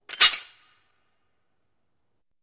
На этой странице собраны реалистичные звуки капканов разных типов: от резкого металлического щелчка до глухого захлопывания.
Звук захлопнувшегося капкана